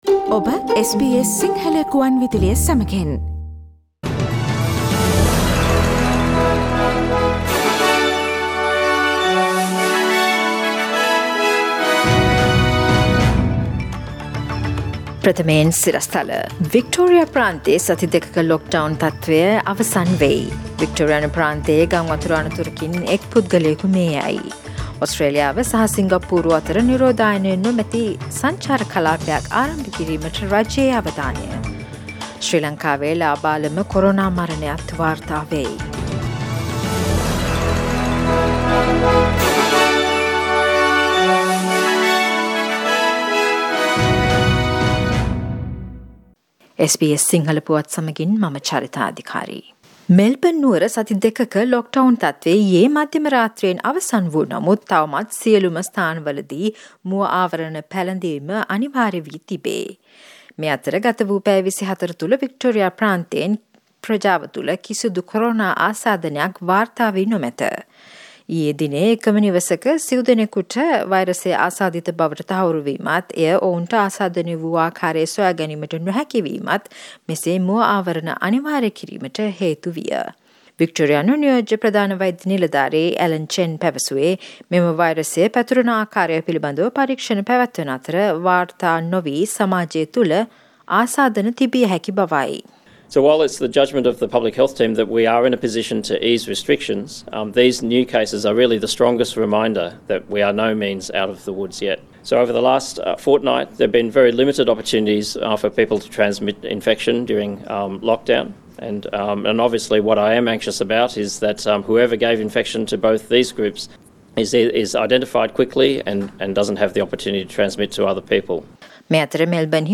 Listen to the latest news from Australia, Sri Lanka, across the globe and the latest news from sports world on SBS Sinhala radio news bulletin – Friday 11th of June 2021.